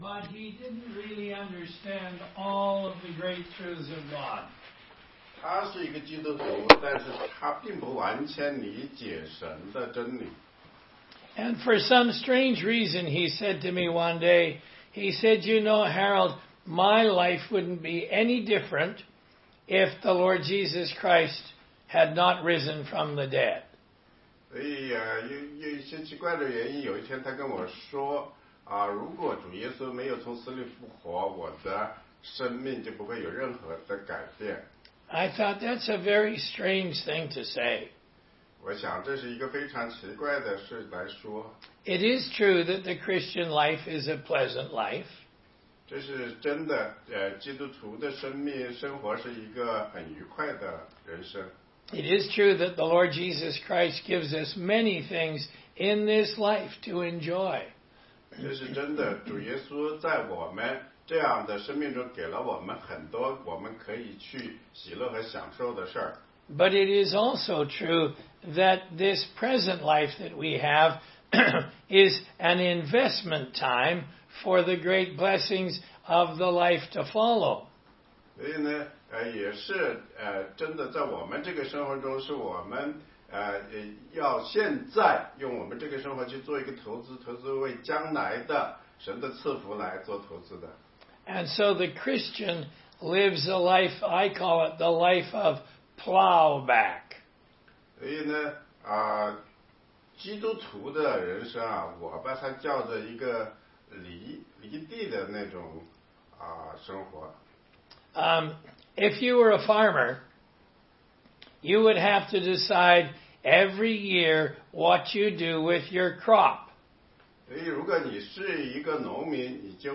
16街讲道录音 - 哥林多前书15章12-34节：基督若没有复活，我们所信的就是枉然